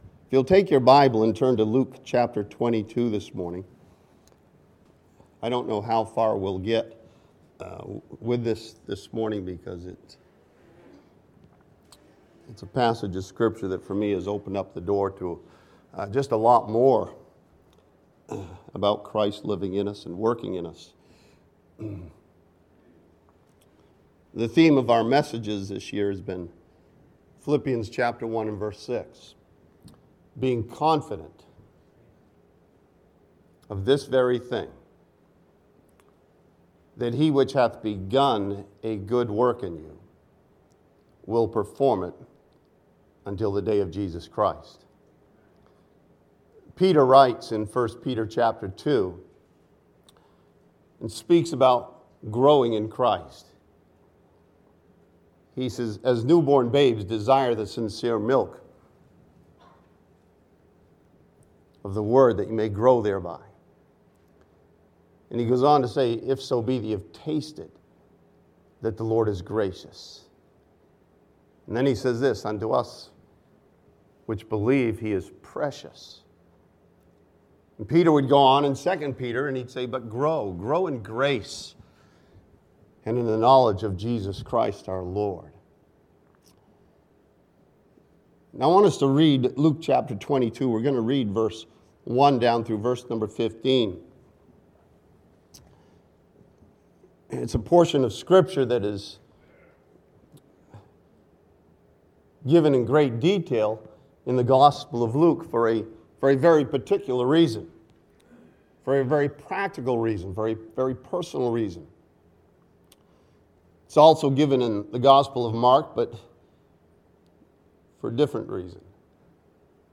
This message from Luke 22 challenges the believer about knowing Jesus from the Word of God.